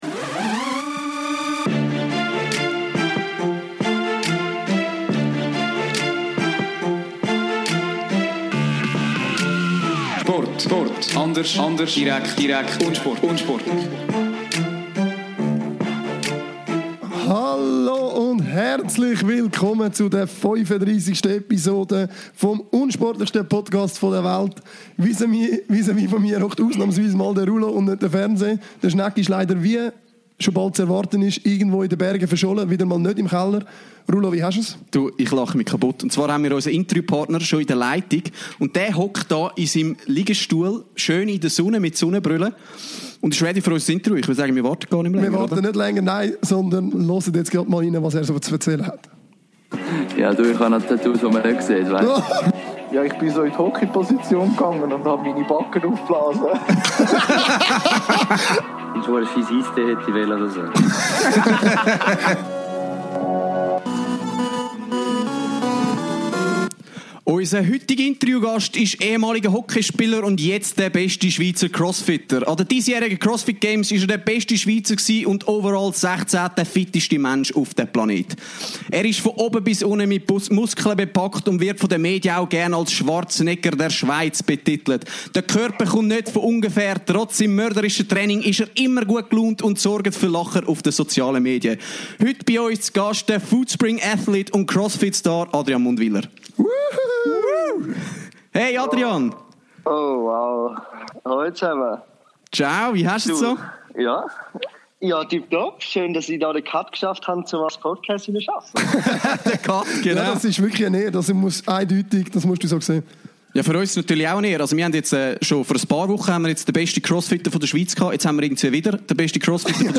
Er beantwortet jede Frage ohne Wimpernzucken und beängstigend ehrlich – vorbildlich unsportlich. Versprochen: Das Interview verursacht Lachkrämpfe.